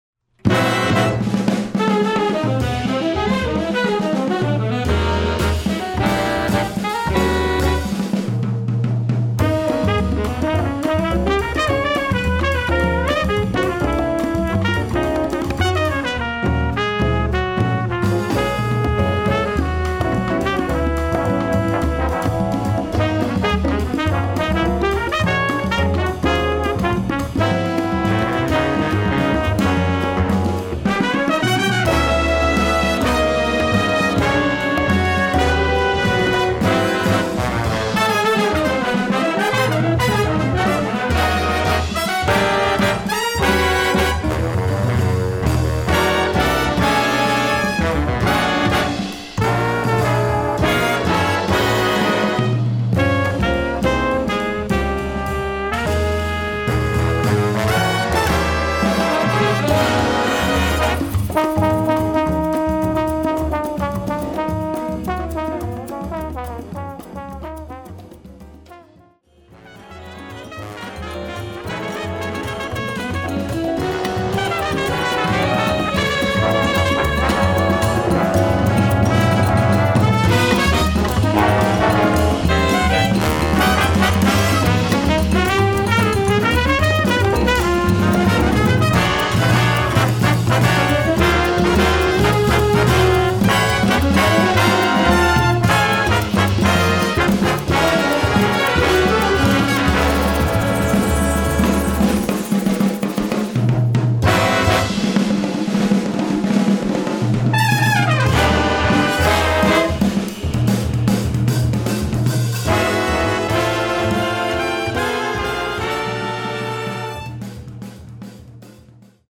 Category: big band
Style: samba
Solos: trombone 4, trumpet 4
Instrumentation: big band (4-4-5, rhythm)